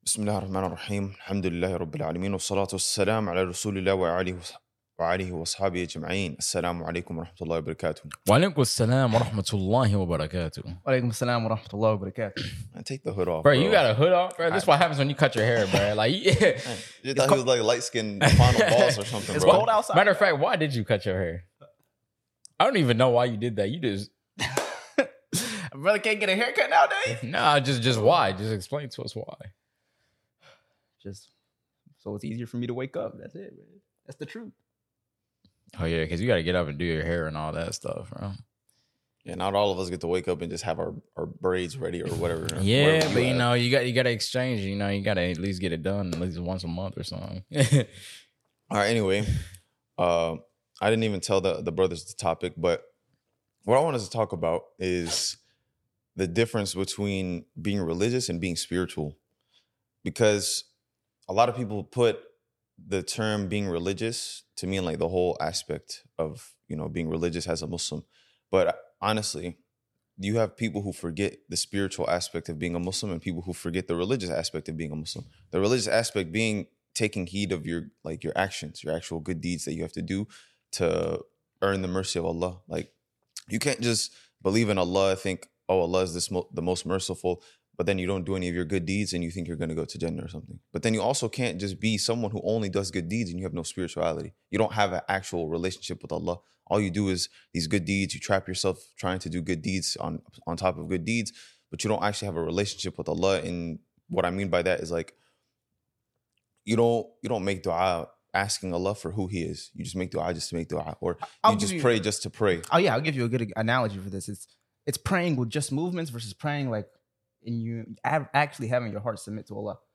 In this podcast we talking about lessons from the story of Prophet Yusuf (AS), developing patience as we saw from him, gratitude, and reminding ourselves of the limited time we have on this earth. DeenTour is a podcast and channel where 3 brothers showcase their love for islam through reminders, brotherhood, motivation, entertainment, and more!